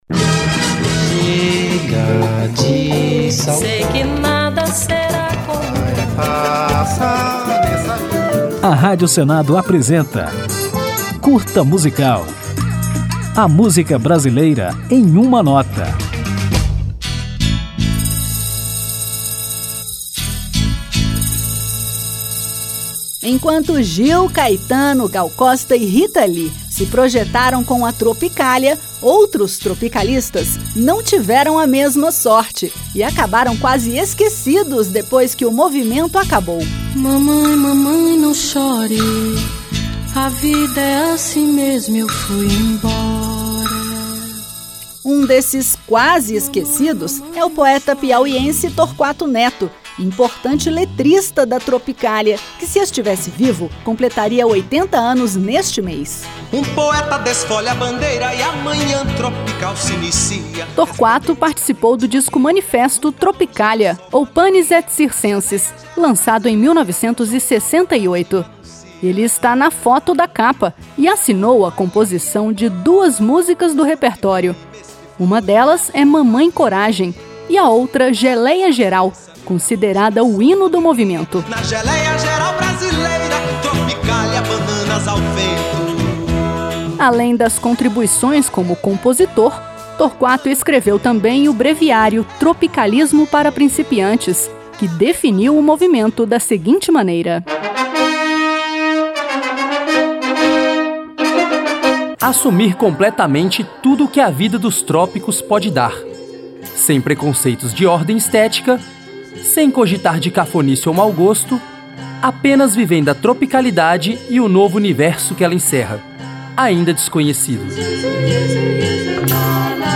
Depois de apresentá-lo, o Curta Musical homenageia o artista tocando Gilberto Gil em Geléia Geral, música que tem letra de Torquato Neto e é considerada o hino da Tropicália.